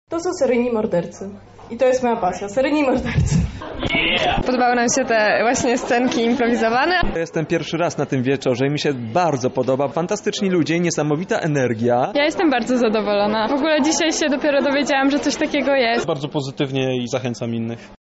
Wczoraj w klubie Dom Kultury zainteresowani wzięli udział w 7 edycji Pecha Kucha Lublin Night.
Ponadto prelegenci nie kryją swojego zadowolenia: